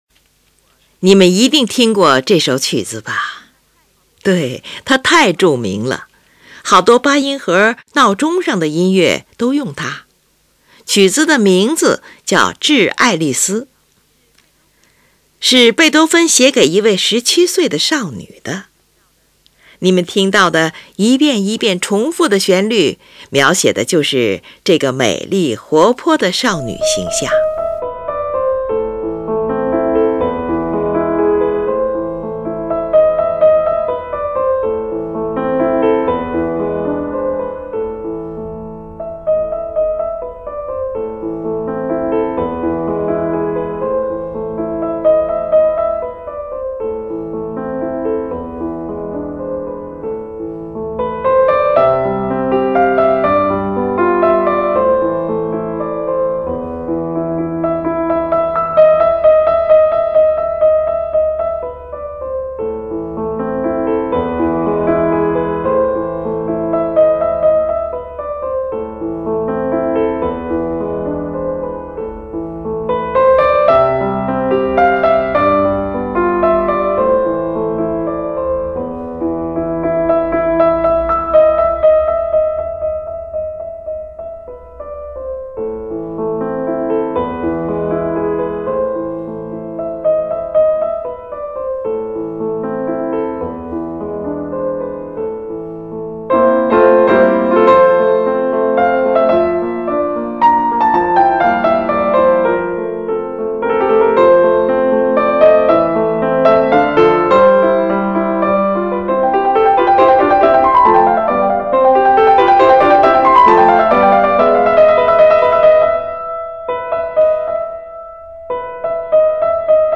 回旋曲式。
B段在F大调上呈现。